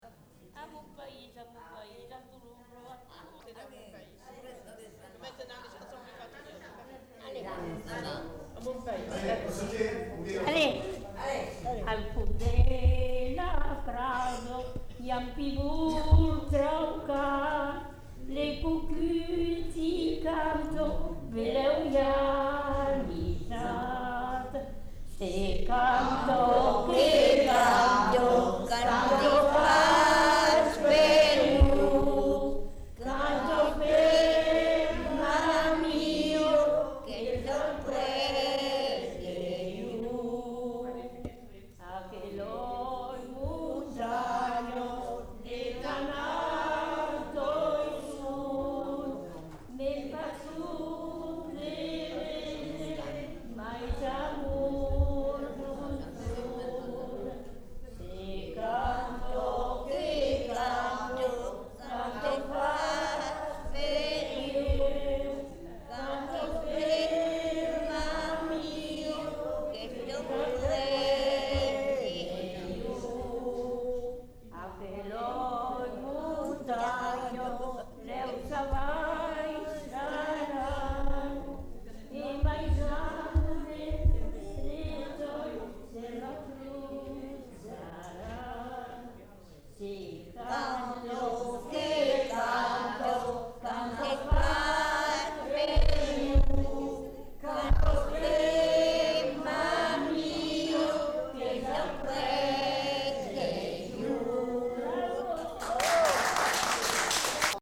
Aire culturelle : Lauragais
Genre : chant
Effectif : 1
Type de voix : voix de femme
Production du son : chanté